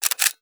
CAMERA_DSLR_Shutter_03_mono.wav